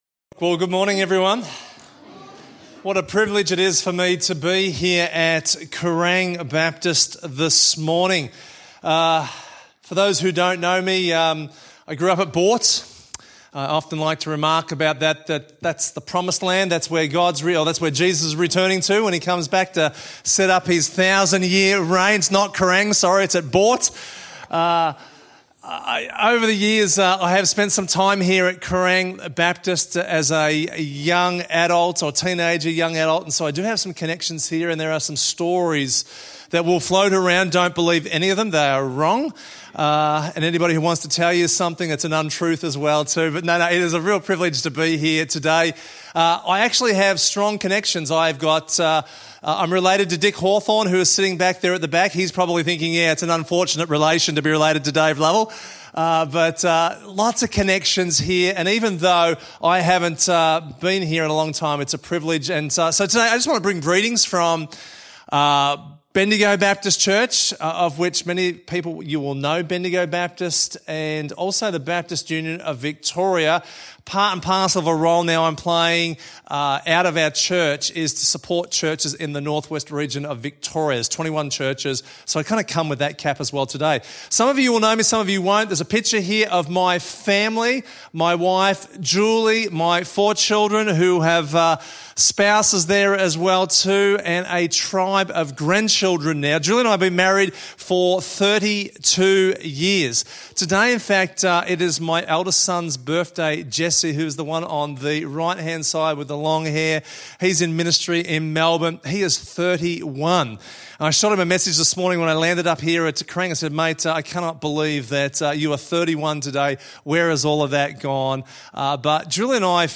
BUV North West Church Support and our guest today